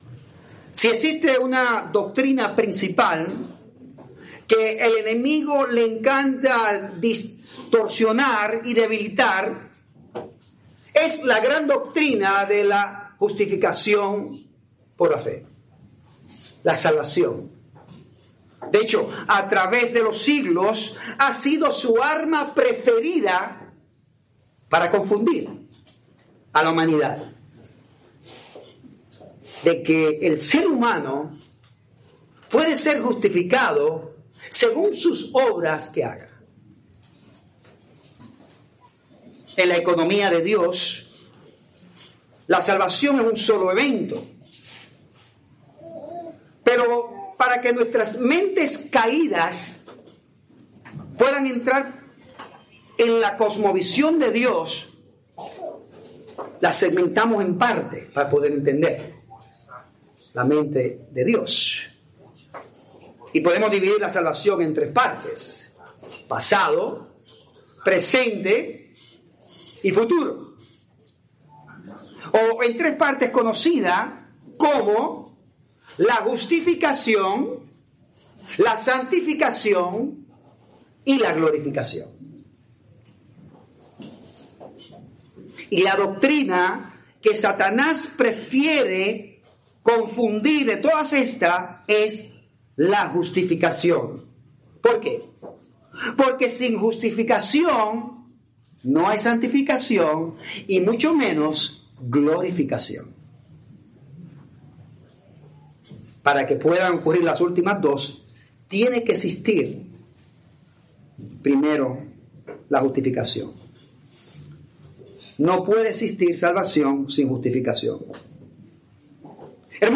Spanish Bible Study